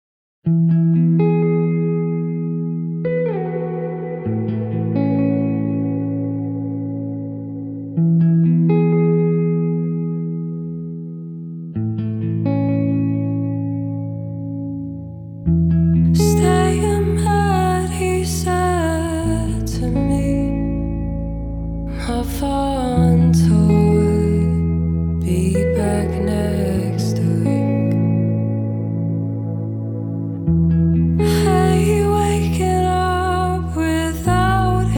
Жанр: Альтернатива / Фолк-рок